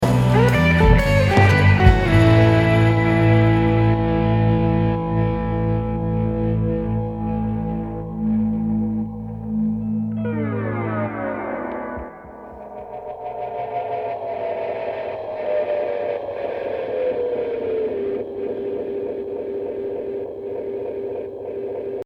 I'm going to upload a digital reference of another part, and the tape 'issue' that seems to feel like a rhythmic volume drop.
Here's that same clip on the tape machine:
View attachment Tape Issue.mp3
It's easier to hear on sustained parts because of the rhythmic cycle of the issue.